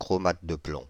Ääntäminen
Synonyymit jaune de chrome Ääntäminen France (Île-de-France): IPA: /kʁo.mat də plɔ̃/ Haettu sana löytyi näillä lähdekielillä: ranska Käännöksiä ei löytynyt valitulle kohdekielelle.